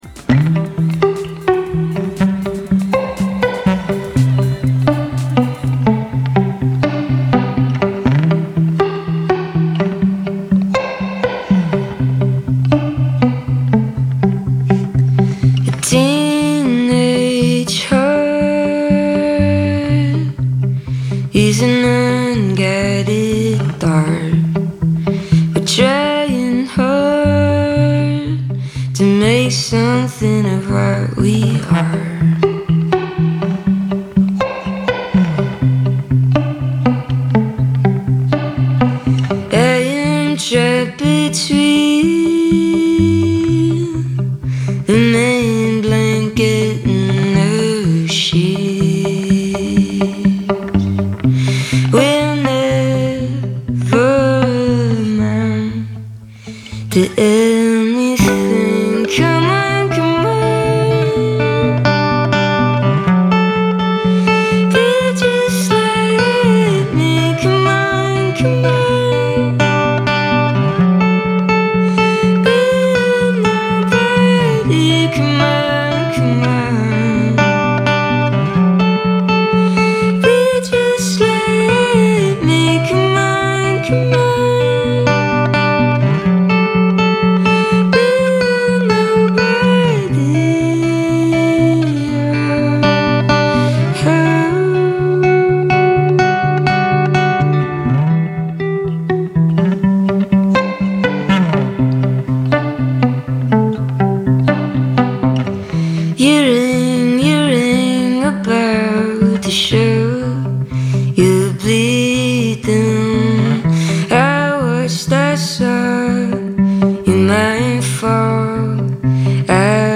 recorded in session at The Sunflower in Belfast
is a singer-songwriter from Derry, Northern Ireland.